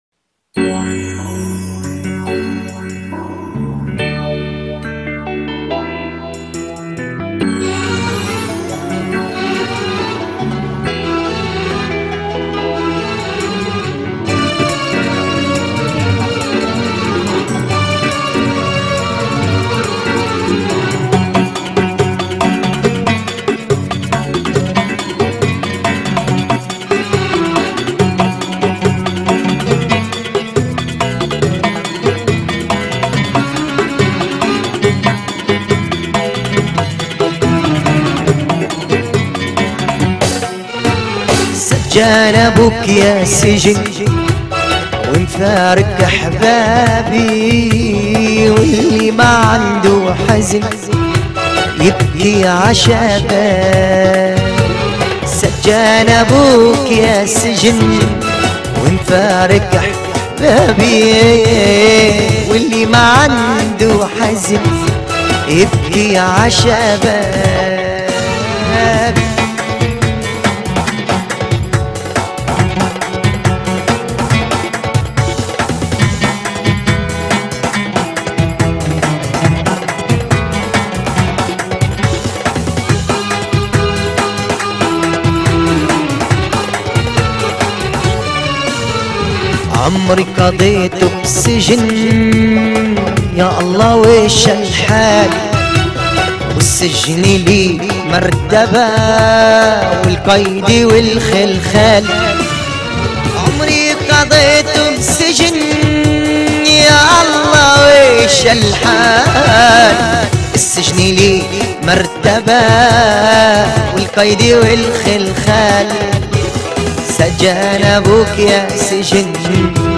أغاني فلسطينيه